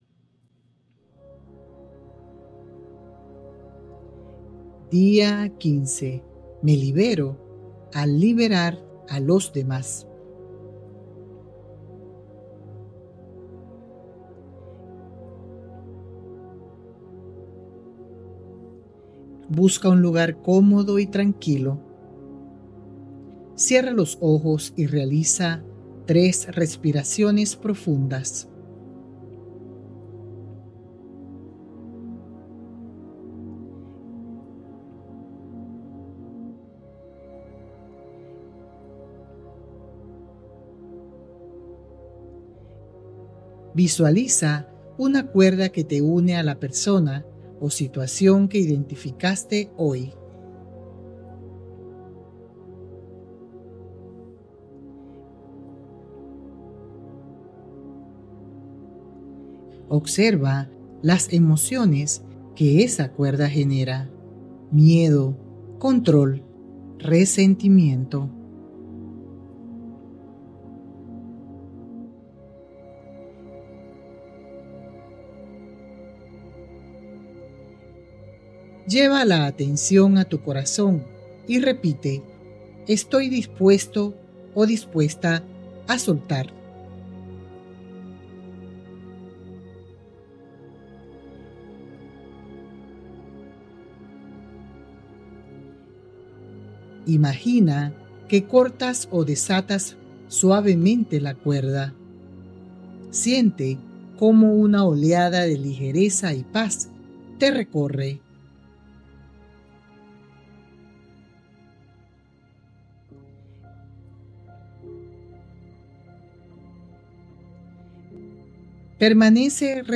🎧 Meditación Guiada: «Me libero al liberar»